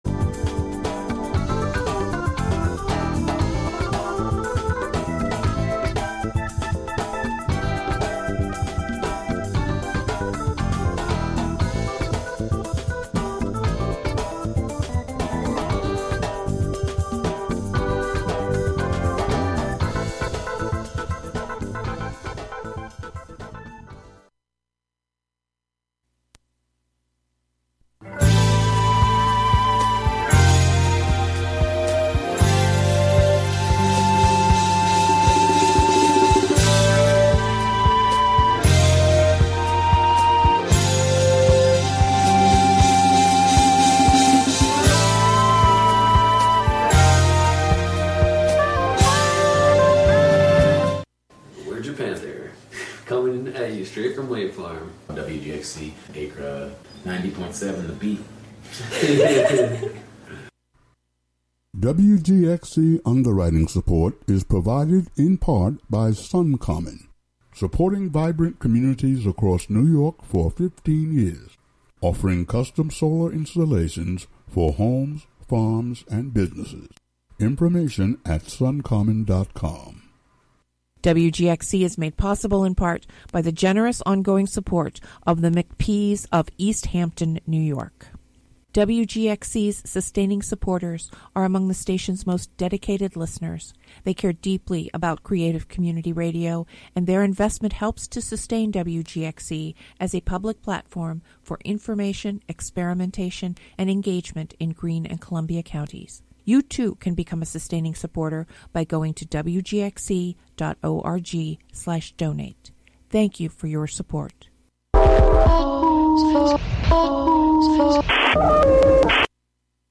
blend of folk music and jazz
as well as solo work by its two acoustic guitarists